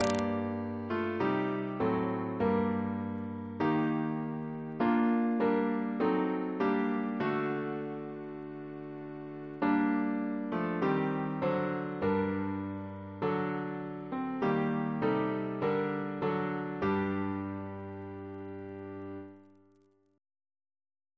Double chant in G minor Composer: Chris Biemesderfer (b.1958) Note: for Psalm 19